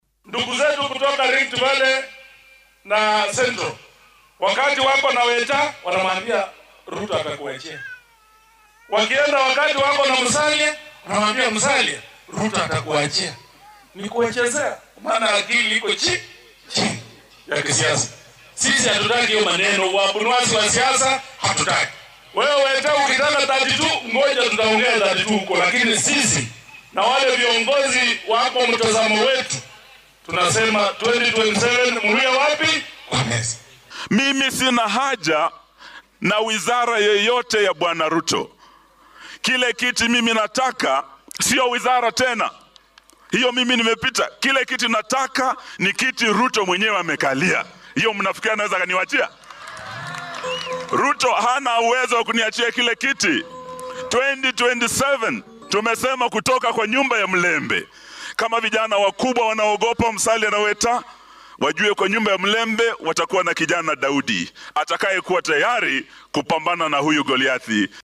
Hoggaamiyaha xisbiga DAP-K Eugene Wamalwa ayaa ugu baaqay dowladda Kenya Kwanza inay diiradda saarto fulinta ballanqaadyadii doorashada halkii ay ka soo jiidan lahayd hoggaamiyeyaasha mucaaradka. Wamalwa ayaa hadalkan ka sheegay ismaamulka Trans Nzoia, isagoo ku dhaliilay dowladda inay ka mashquushay siyaasadda halkii ay ka qaban lahayd ballanqaadyadii ay shacabka u samaysay.